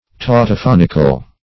Search Result for " tautophonical" : The Collaborative International Dictionary of English v.0.48: Tautophonical \Tau`to*phon"ic*al\, a. Pertaining to, or characterized by, tautophony; repeating the same sound.
tautophonical.mp3